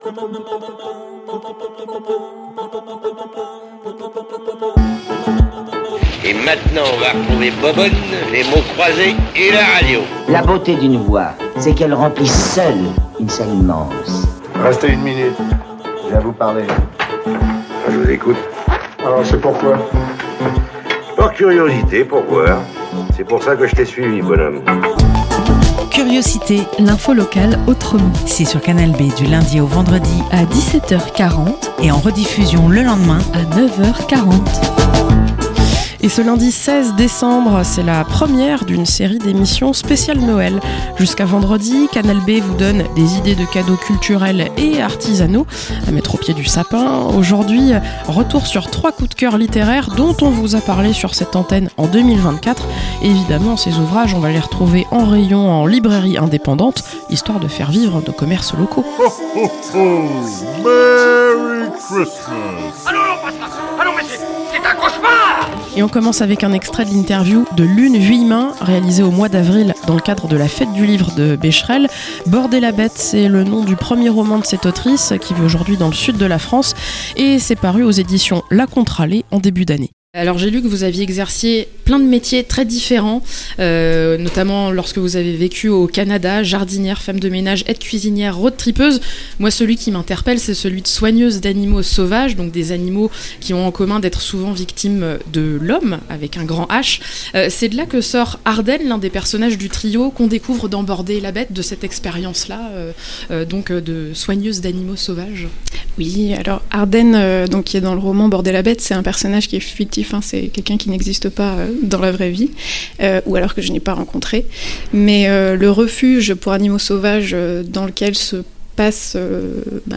- Ré-écoutez les extraits de 3 interviews d'autrices autour de coups de coeur littéraires 2024